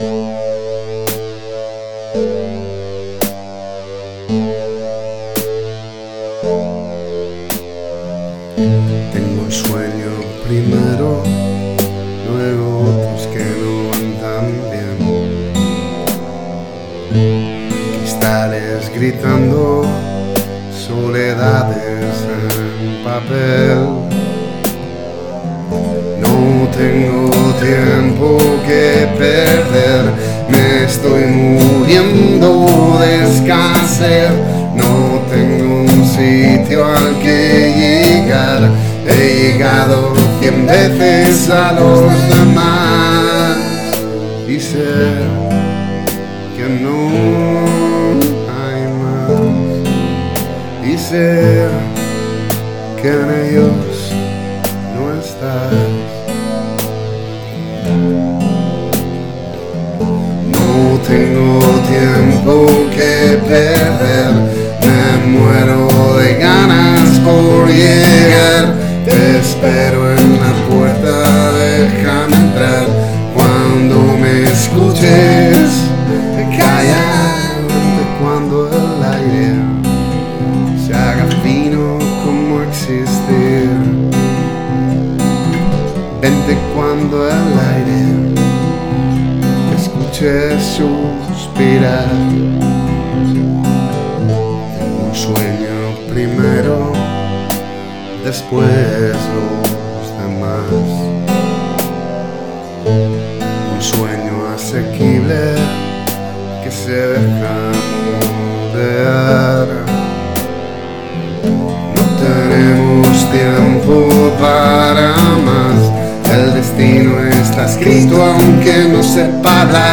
(2) A G A F